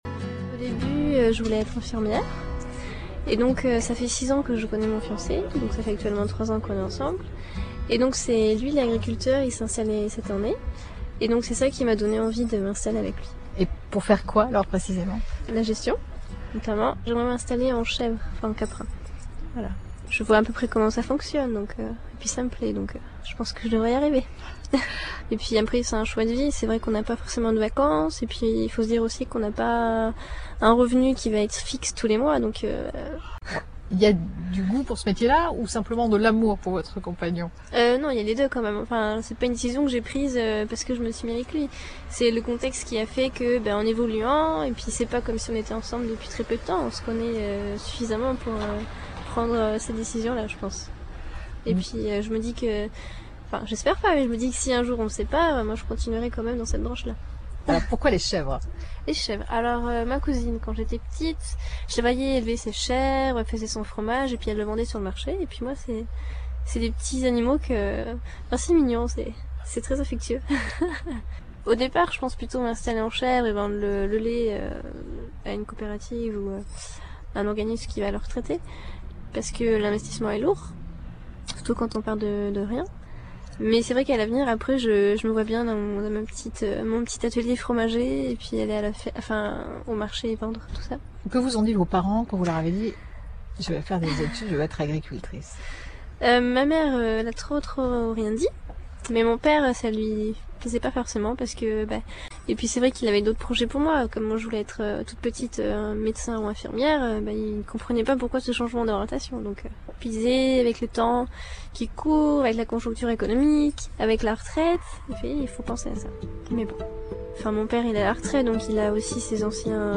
Mais cette jeune femme a trouvé sa vocation et en parle avec envie.
jeune-c3a9leveuse-de-chc3a8vres.mp3